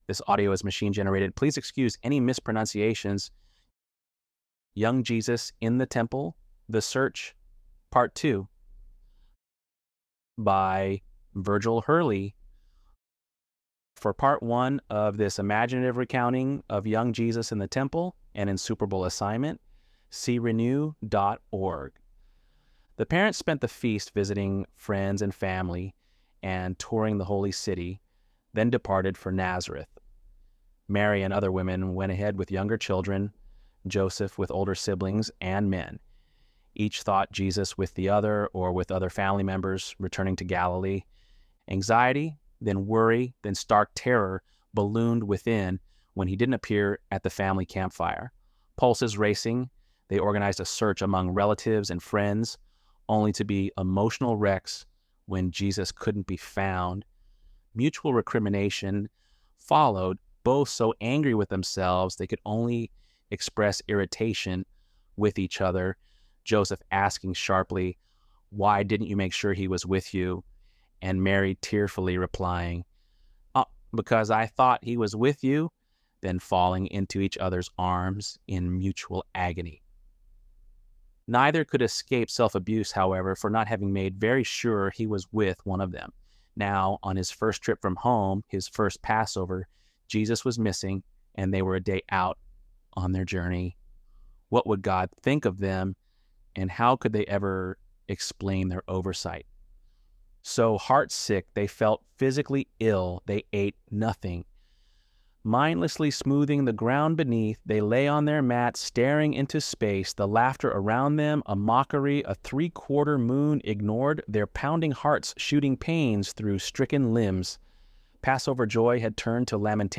ElevenLabs_10.23.mp3